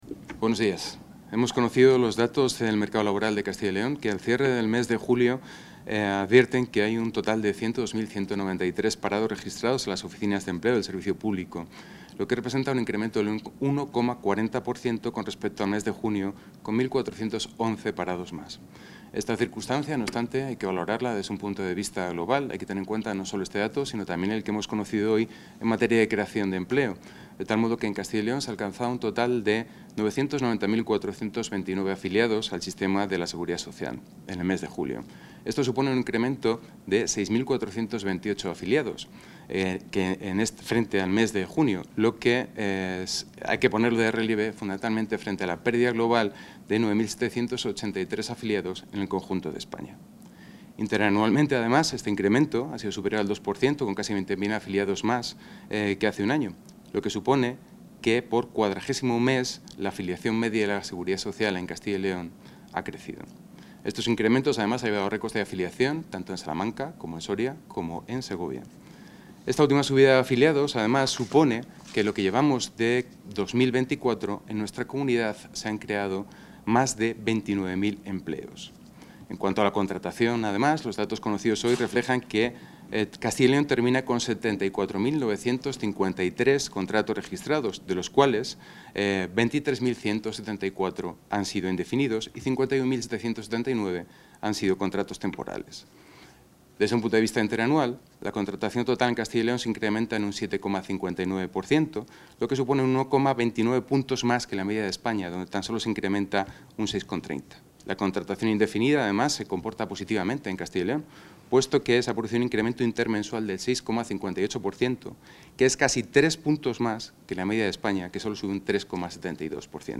El gerente del Servicio Público de Empleo de Castilla y León (Ecyl), Jesús Blanco Martínez, ha valorado hoy los datos de paro registrado correspondientes al mes de julio de 2024.